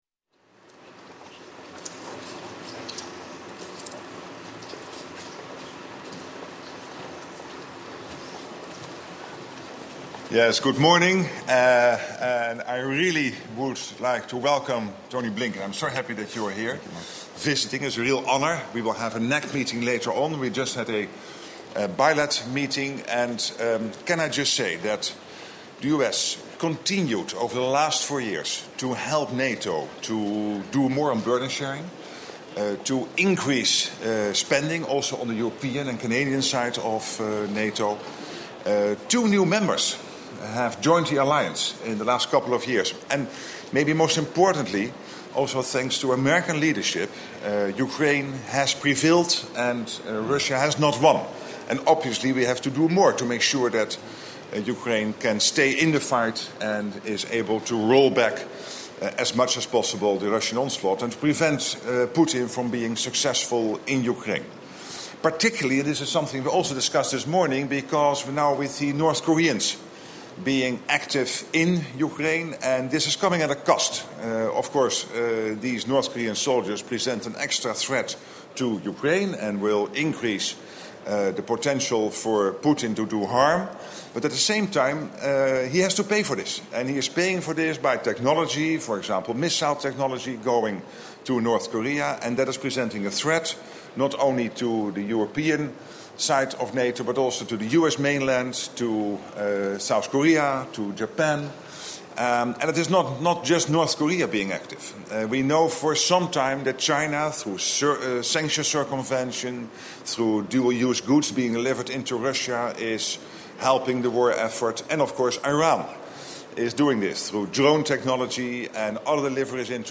Выступления Генерального секретаря НАТО Марка Рютте и государственного секретаря США Энтони Блинкена